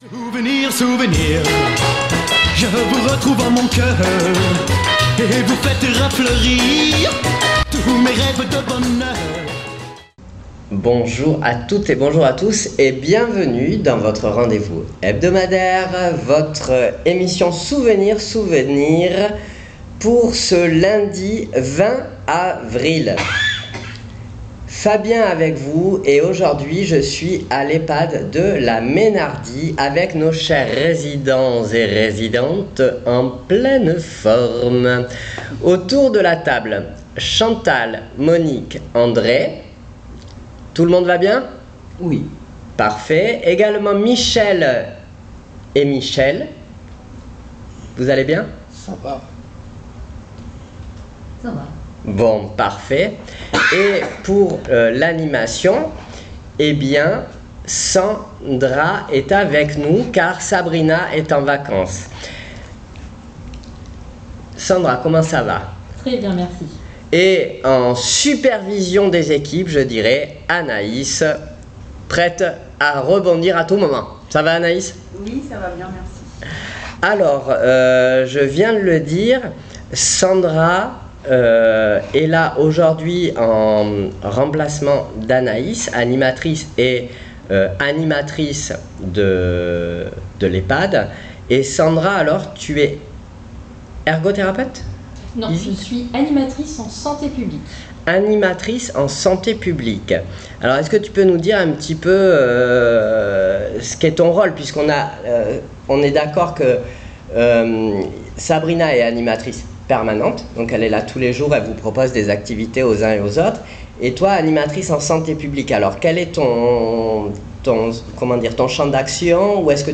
Souvenirs Souvenirs 20.04.26 à l'Ehpad de La Meynardie " La voix "